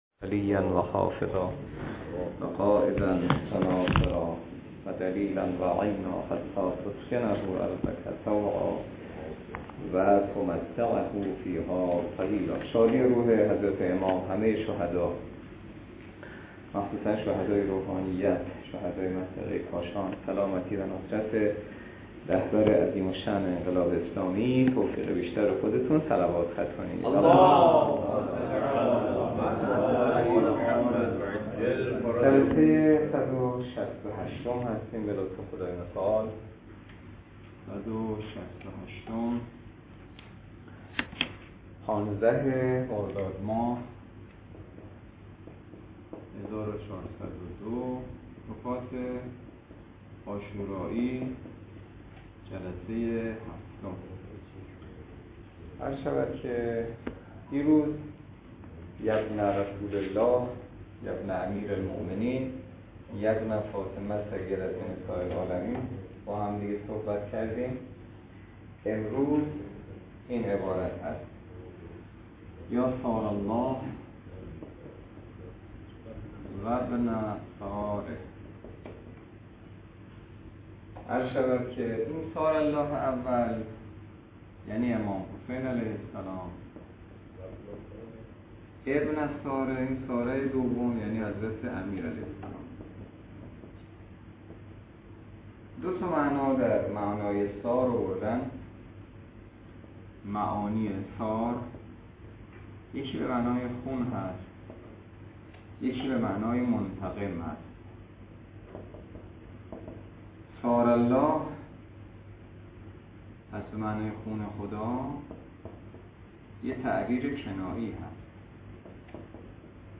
درس فقه الاجاره نماینده مقام معظم رهبری در منطقه و امام جمعه کاشان - جلسه صد و شصت و هشت .